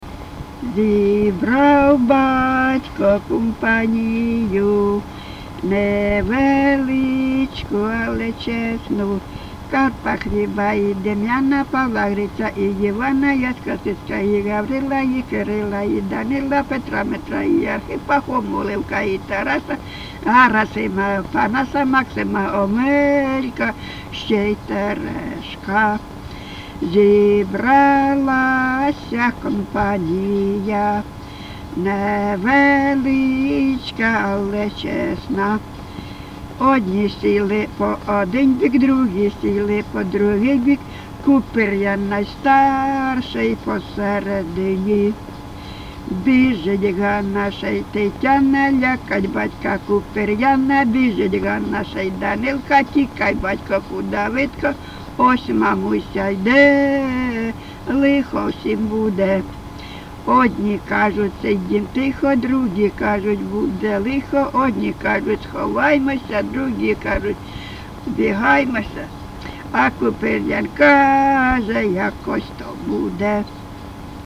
ЖанрЖартівливі
Місце записус. Привілля, Словʼянський (Краматорський) район, Донецька обл., Україна, Слобожанщина